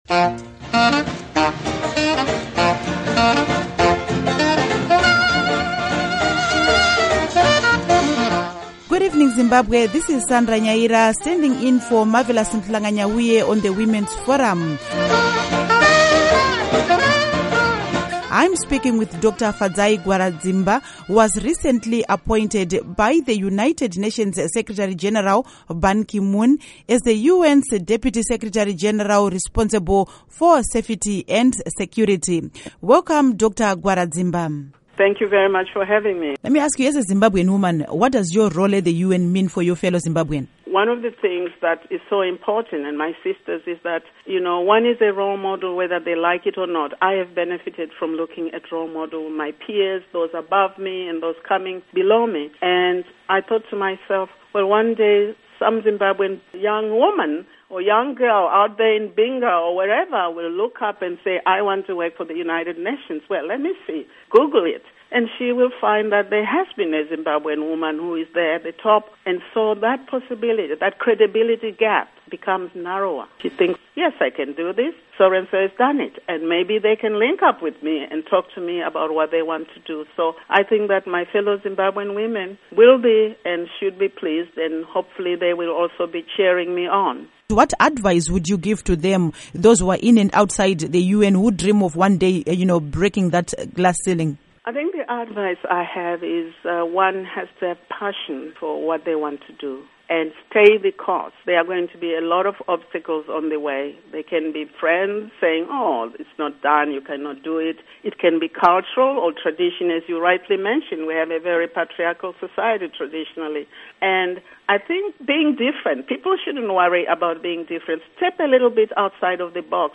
Interview with Dr. Fadzai Gwaradzimba, UN Deputy Sec.